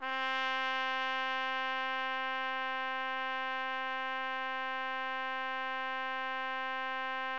TRUMPET    7.wav